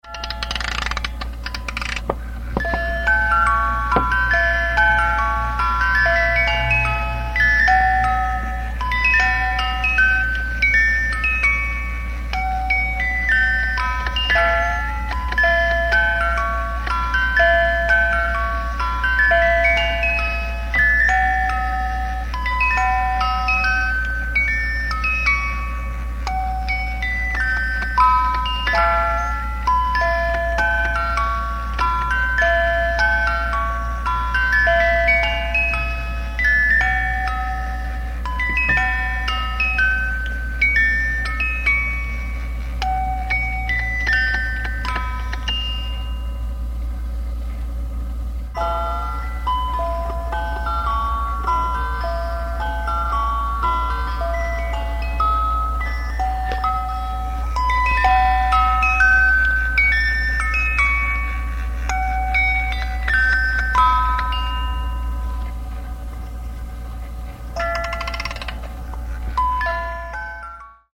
Cajita de Música
Les comparto el sonido de mi cajita musical que me ha acompañado por 40 años y todavía tiene cuerda para rato.